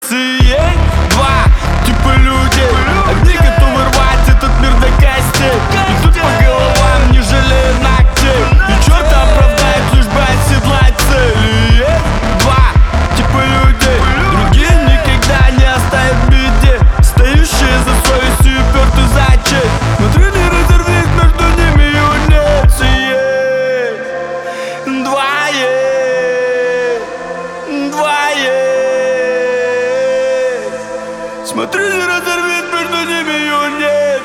• Качество: 320, Stereo
громкие
сильные
серьезные